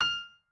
piano2_5.ogg